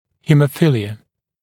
[ˌhiːmə’fɪlɪə ] [ˌhe-][ˌхи:мэ’филиэ ] [ˌхэ-]гемофилия, кровоточивость